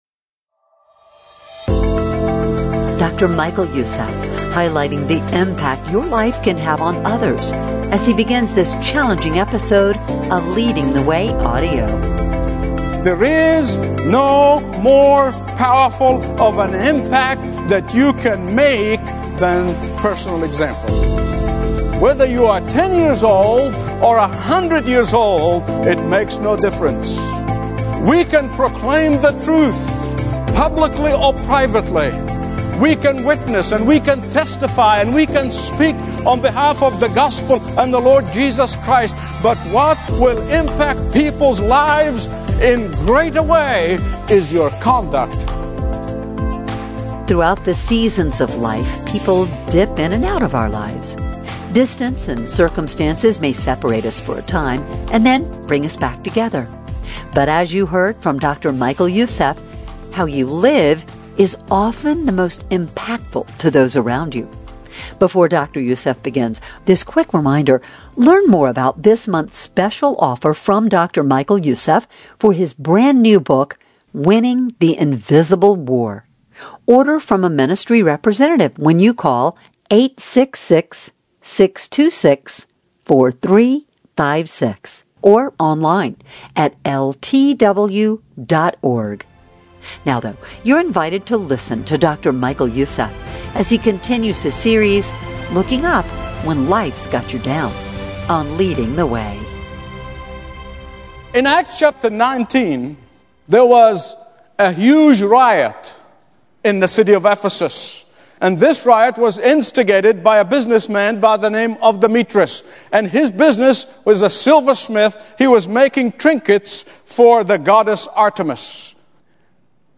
Stream Expository Bible Teaching & Understand the Bible Like Never Before.